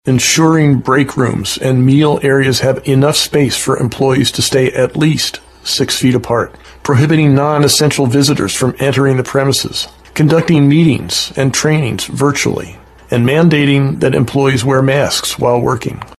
WOLF SPEAKS ON SOCIAL DISTANCING ORDER